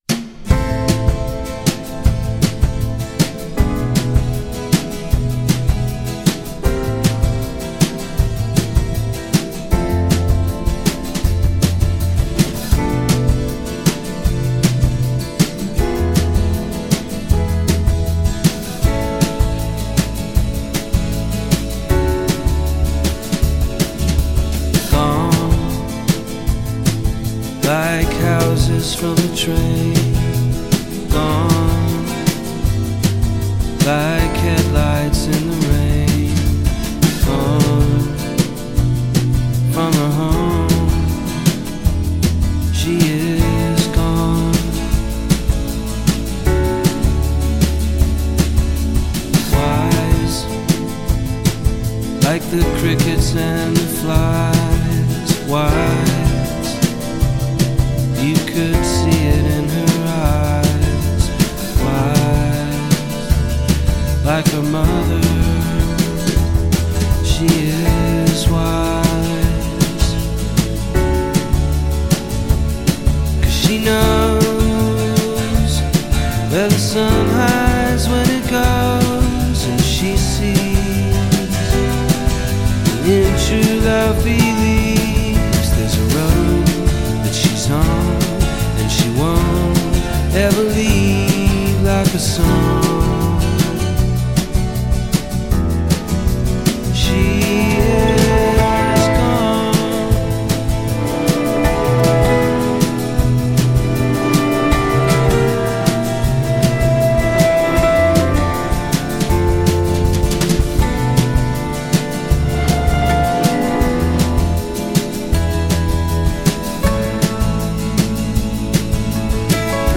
It’s gently intoxicating